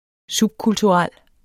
Udtale [ ˈsub- ]